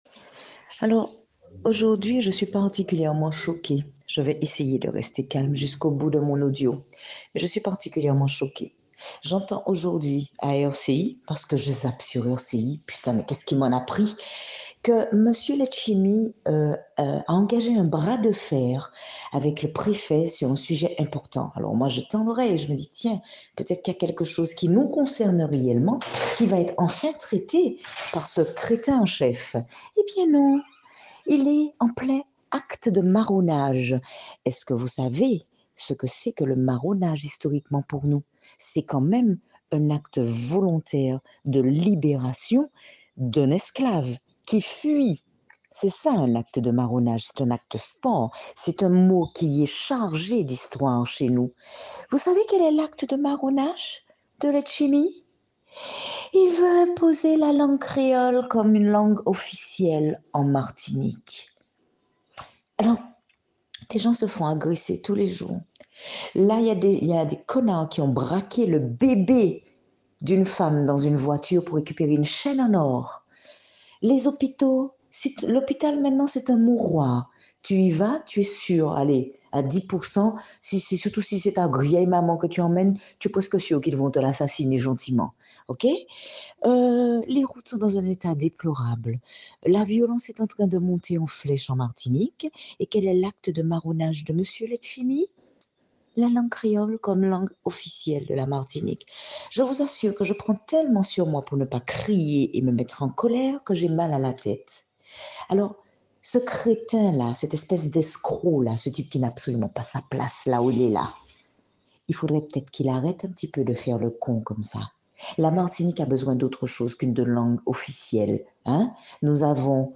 Elle prend de la hauteur et ses mots sont stratosphériques.